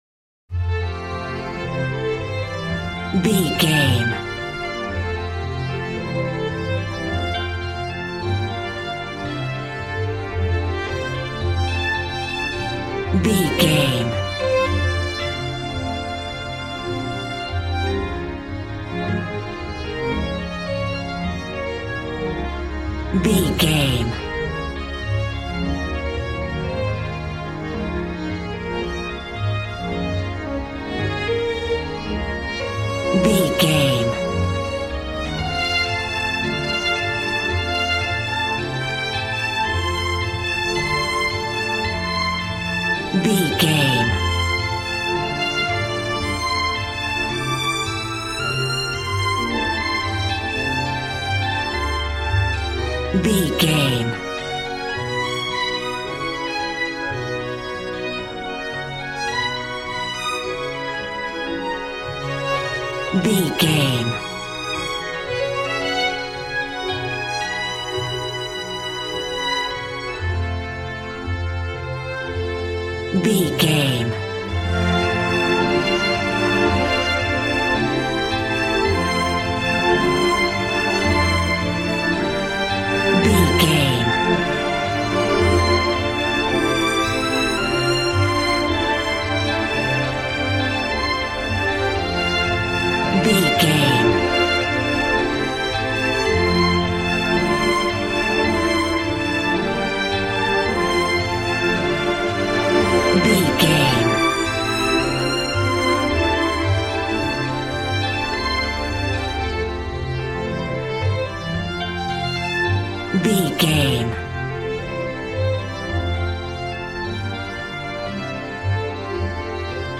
Ionian/Major
joyful
conga
80s